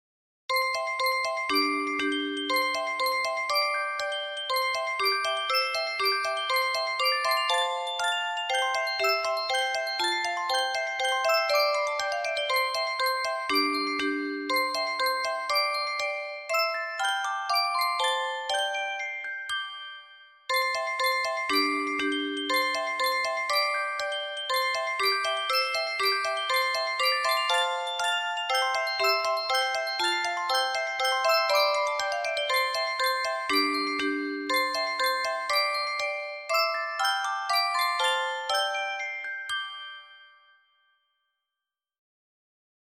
Dark arrange version of famous works
traditional song Japan